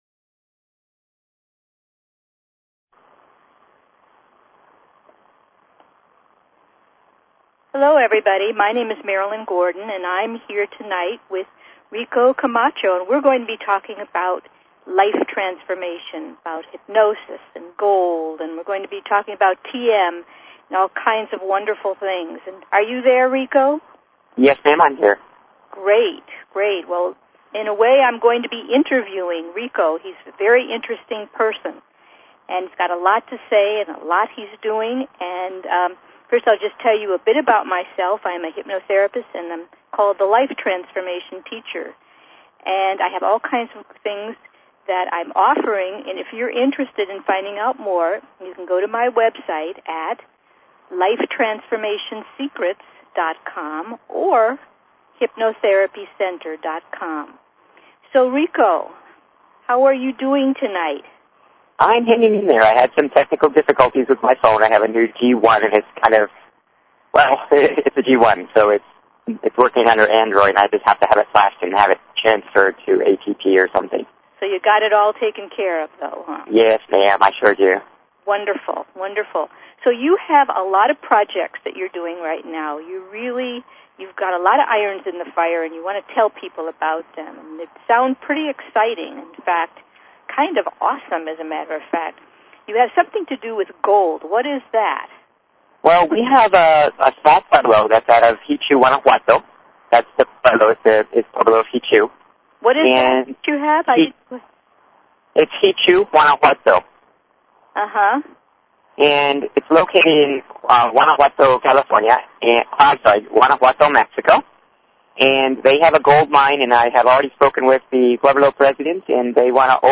Talk Show Episode, Audio Podcast, Hypnosis_and_TM_Discussion_Hour and Courtesy of BBS Radio on , show guests , about , categorized as